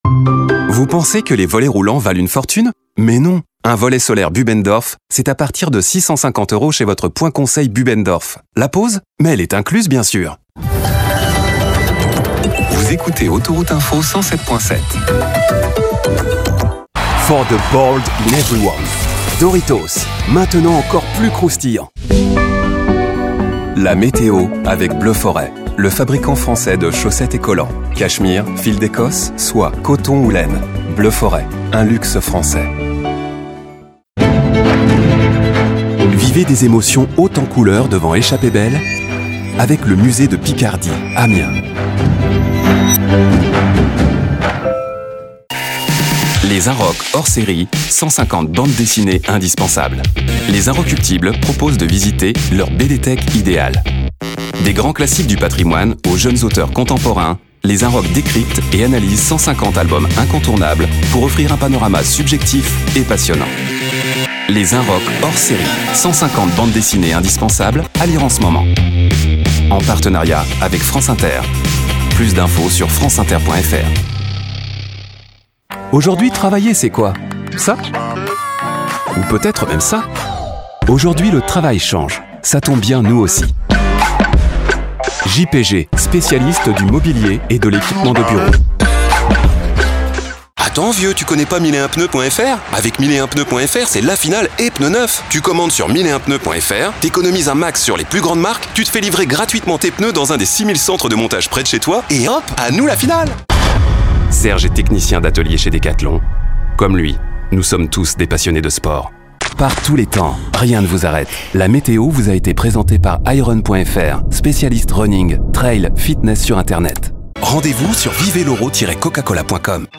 Natuurlijk, Vertrouwd, Vriendelijk, Warm, Zakelijk
Commercieel
He has a professional recording studio and is highly responsive.